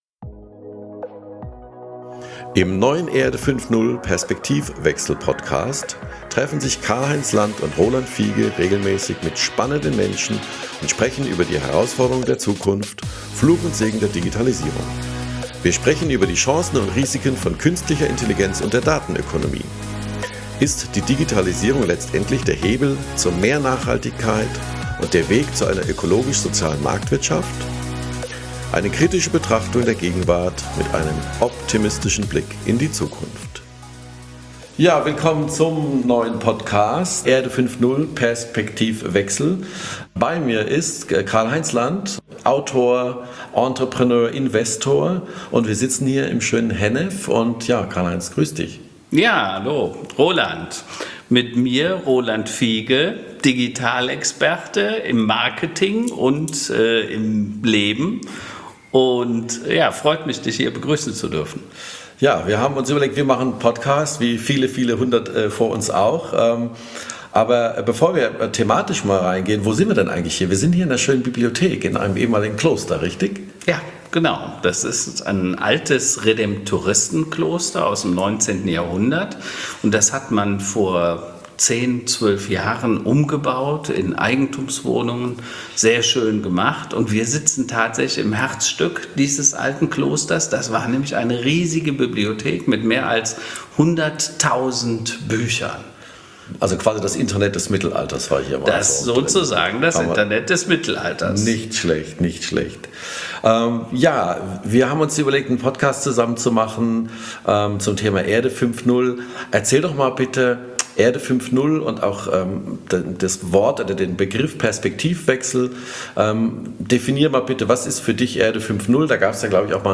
Fulminanter Auftakt, aufgenommen in einer ehemaligen Bibliothek eines Klosters. Digitalisierung, was muss besser werden, welche Risiken bergen die Quasi Monopole aus Silicon Valley? Bringt Covid-19 die Versäumnisse der letzten Jahrzehnte zum Vorschein?